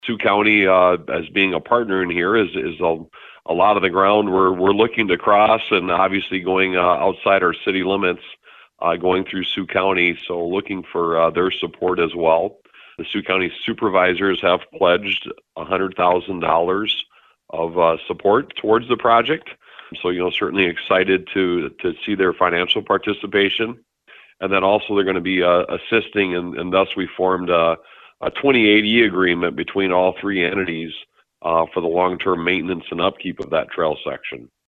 Sioux Center City Manager Scott Wynia tells us more.